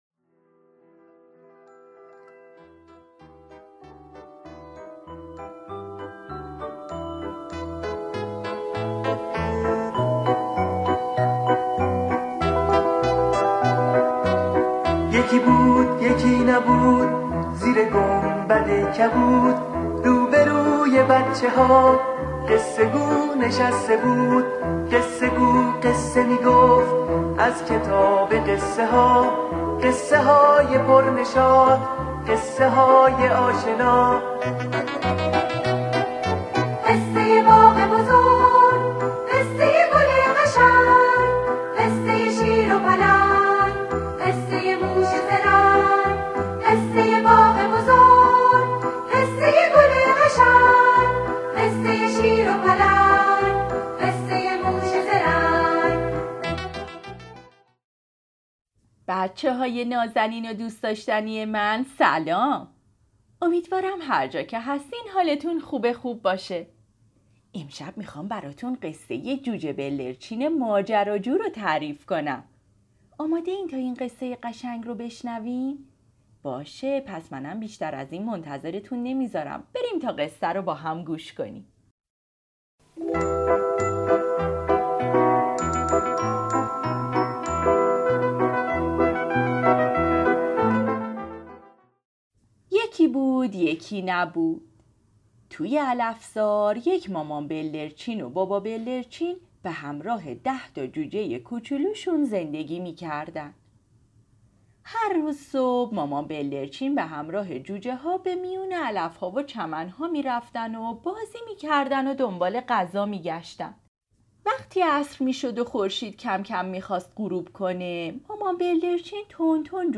قصه صوتی جوجه بلدرچین ماجراجو - متفرقه با ترافیک رایگان